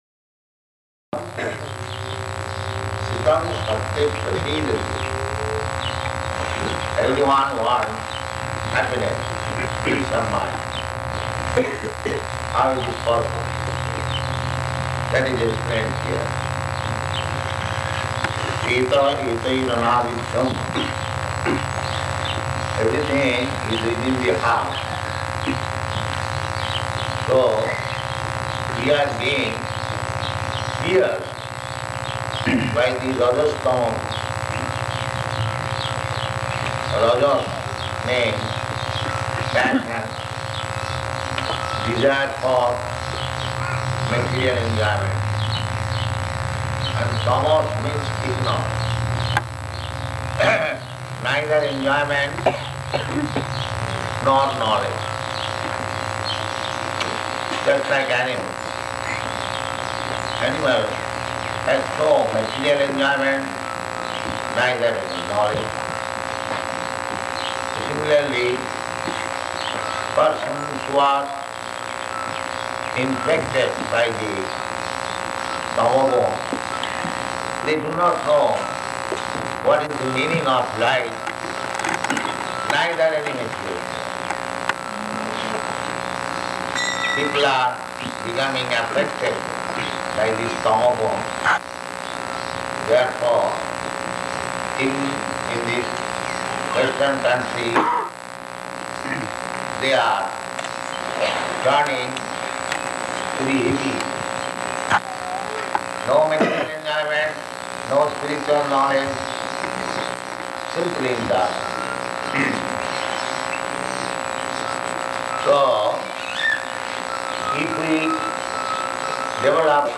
Location: New Vrindaban
[recorded on faulty equipment]